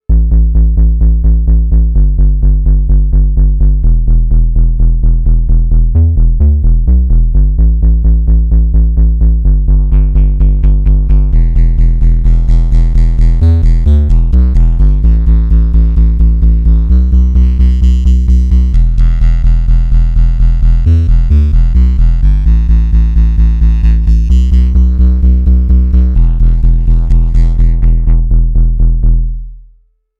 Bass
waldorf_quantum_test__bass_1.mp3